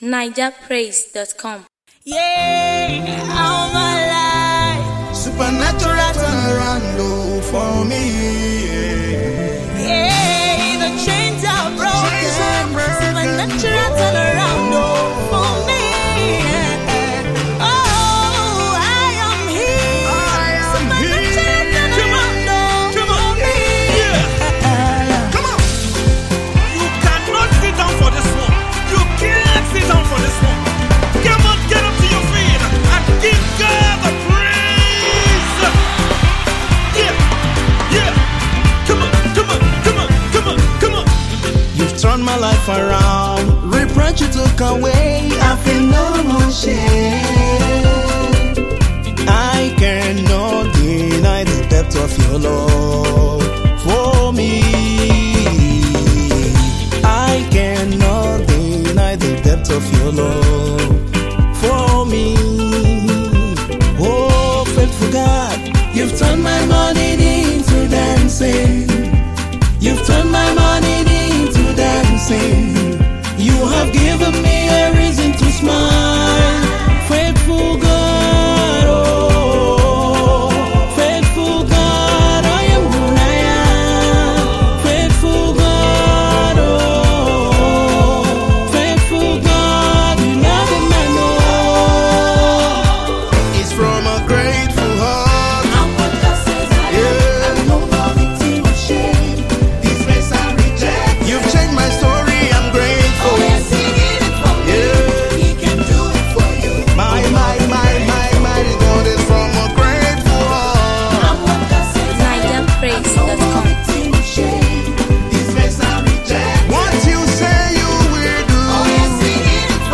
Christian recording artist